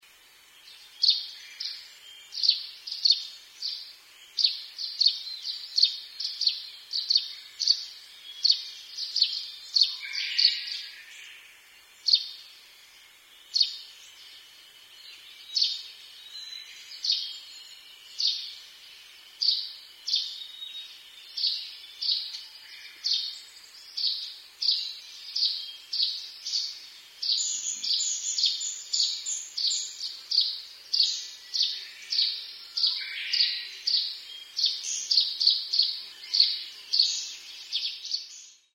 nyunaisuzume_c1.mp3